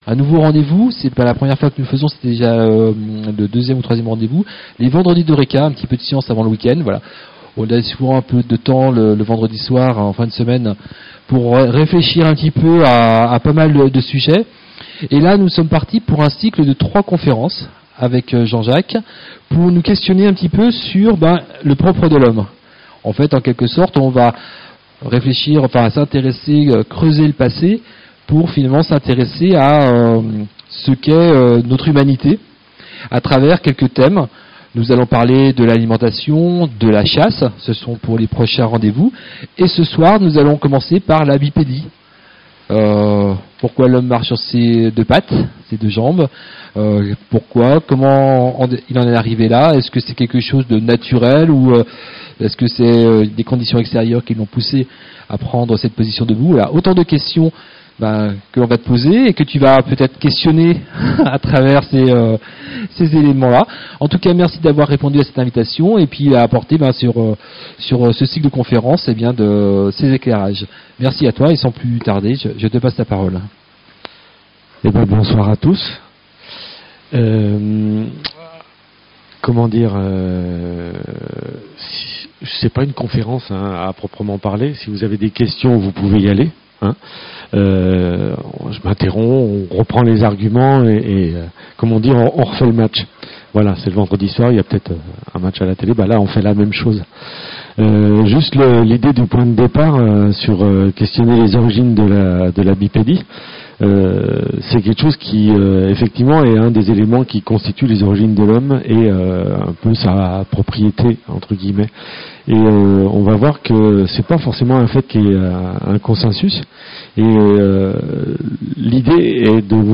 Le premier temps fort s'est tenu le vendredi 27 mars avec la conférence " La bipédie est-elle le propre de l'Homme ? ".
Ecoutez la conférence et retrouvez toutes les questions du public sur l'enregistrement audio .